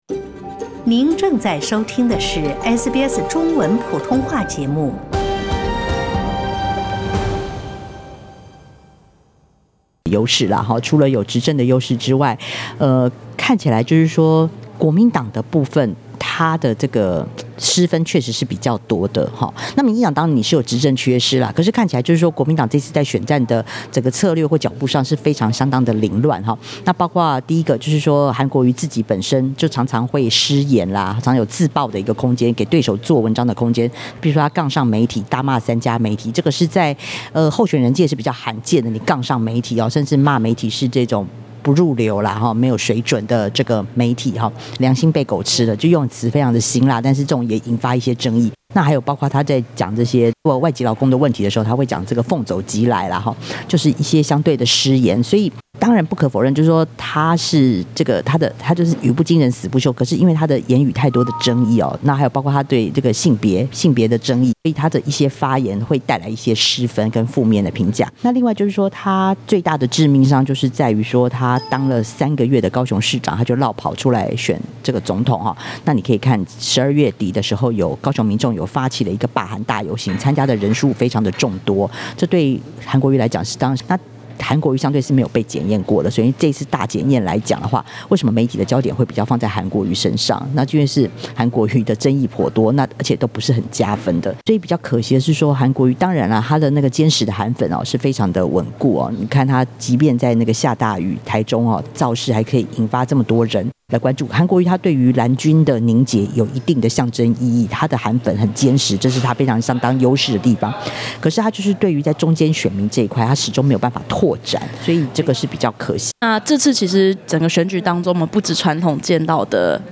小党派正在分散蓝绿阵营的票源。点击上方图片收听采访录音。